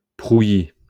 [pʁuji](info) ìsch a frànzeescha G’mainda mìt 553 Iiwoohner (Schtànd 1.